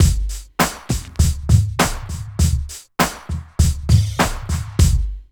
88 DRUM LP-R.wav